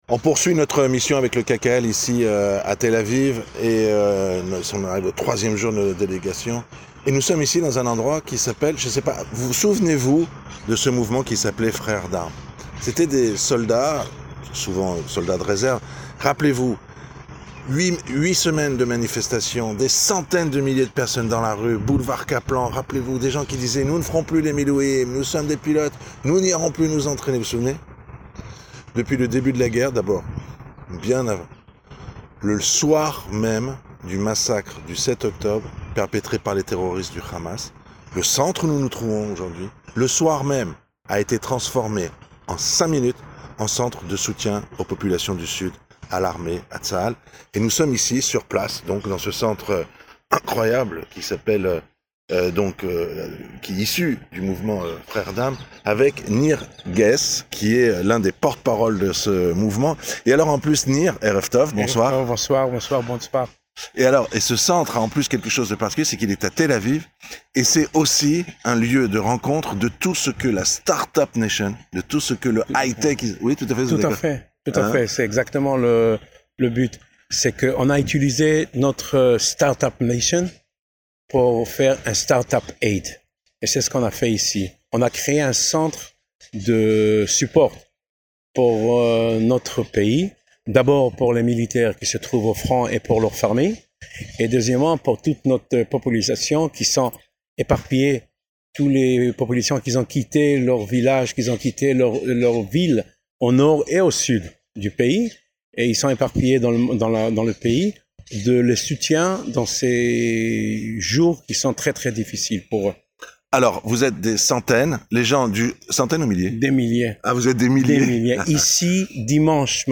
Édition spéciale en direct de Tel Aviv avec nos envoyés spéciaux et le KKL.